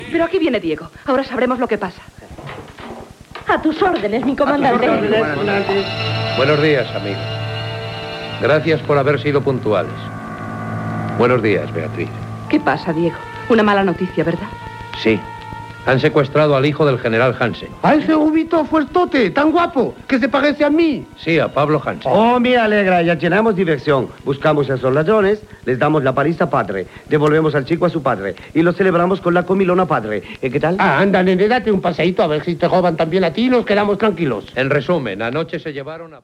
Ficció
Aquest serial radiofònic es va emetre des d'octubre del 1953 a juny del 1958.